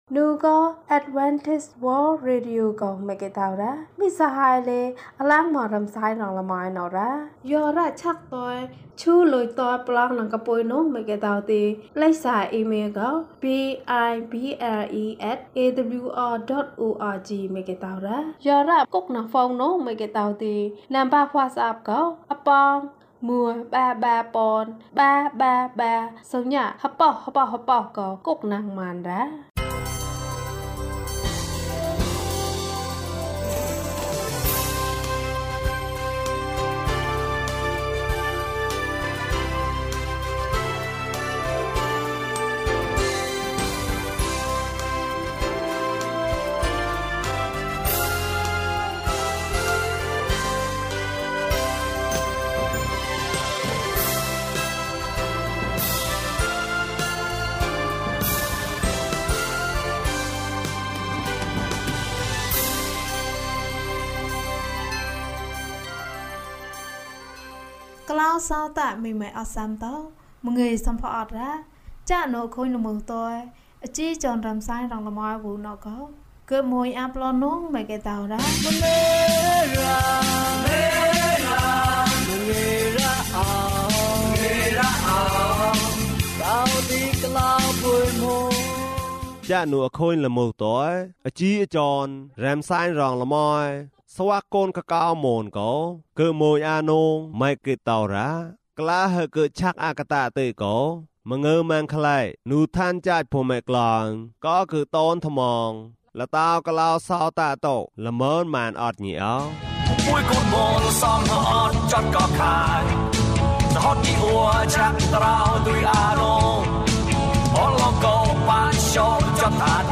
သခင်ယေရှု မင်းကို ချစ်တယ်။၀၁ ကျန်းမာခြင်းအကြောင်းအရာ။ ဓမ္မသီချင်း။ တရားဒေသနာ။